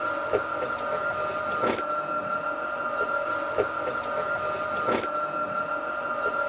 Golf Cart Loop.wav